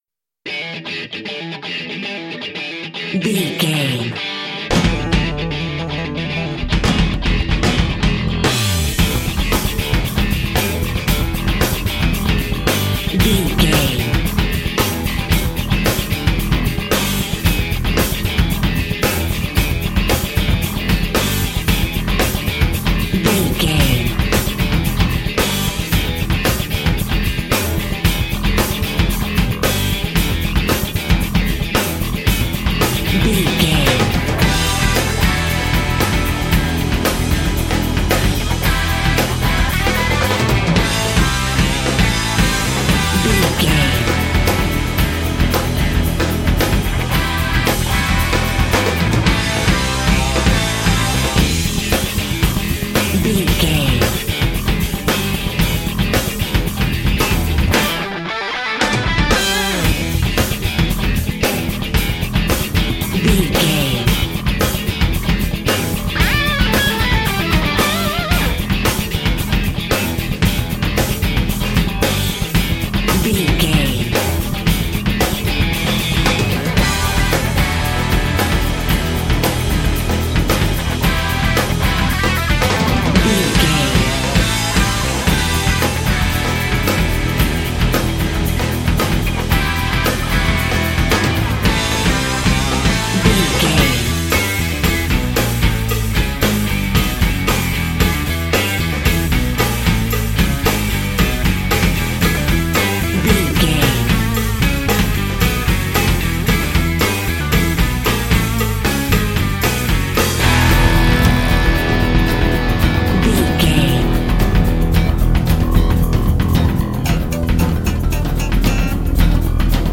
Ionian/Major
drums
electric guitar
bass guitar
Sports Rock
hard rock
lead guitar
aggressive
energetic
intense
powerful
nu metal
alternative metal